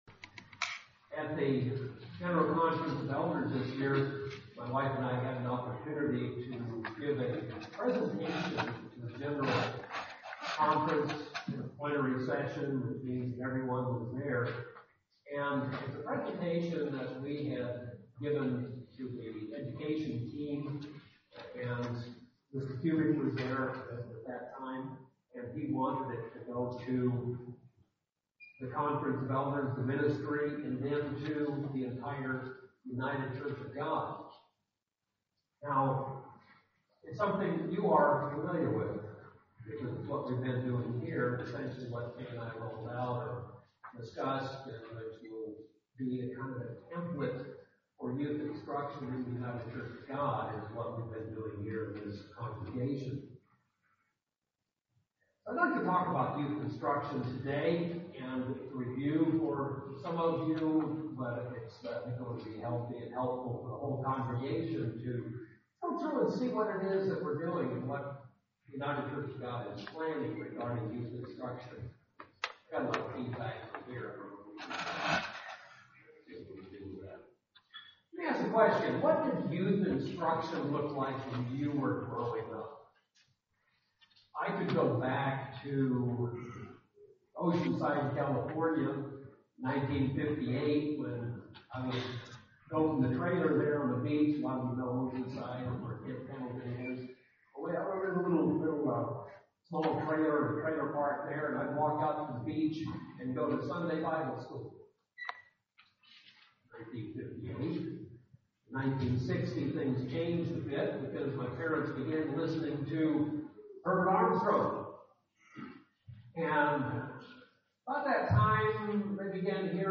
If the church is to grow – the retention and development of our children is essential. In this sermon we will examine whether we as parents, and as a church are providing Youth Instruction according to God's direction.